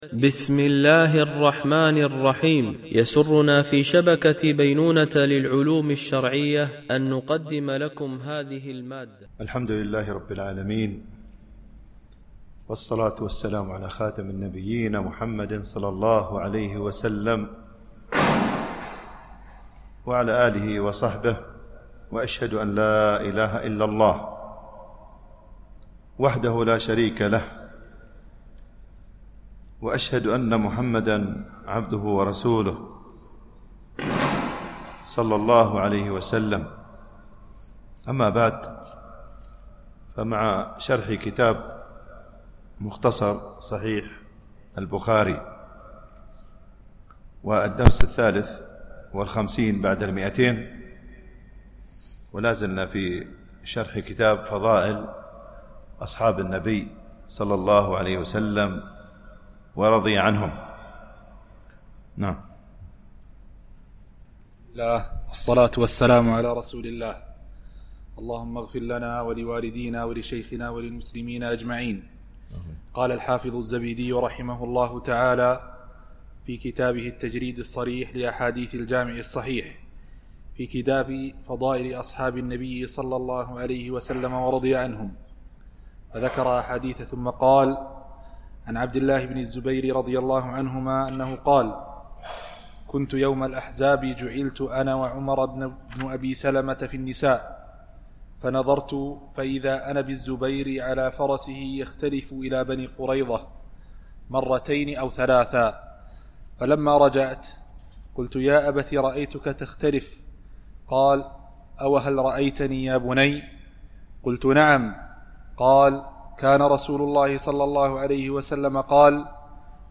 - الجزء الخامس - الحديث 1535 - 1539 ) الألبوم: شبكة بينونة للعلوم الشرعية التتبع: 253 المدة: 55:22 دقائق (25.36 م.بايت) التنسيق: MP3 Mono 44kHz 64Kbps (CBR)